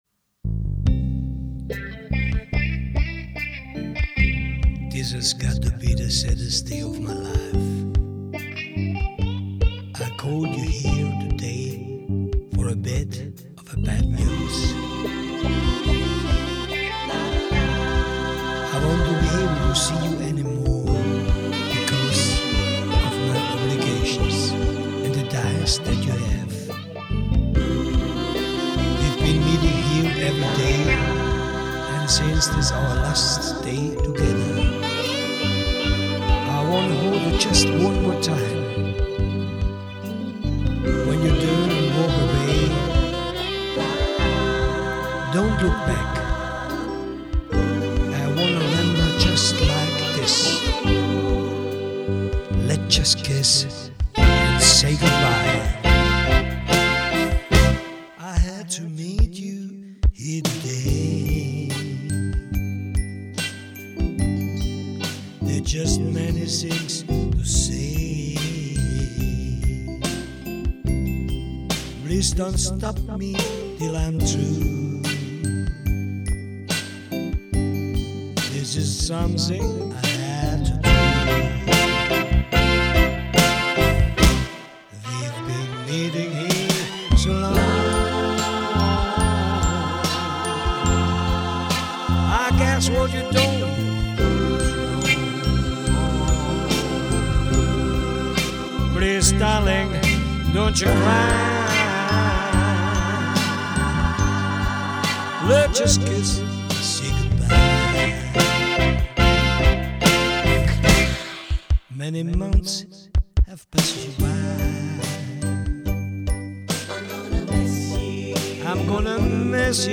Schmusesongs